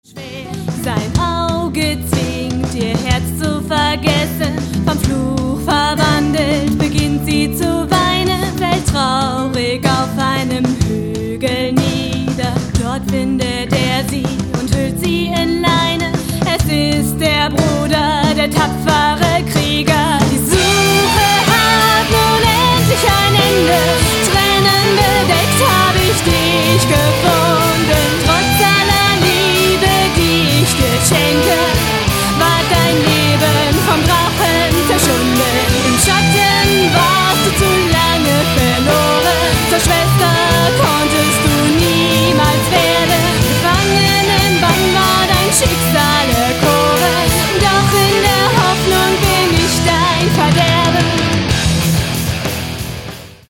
Schlagzeug
Bass
Gesang
Gitarre / Violine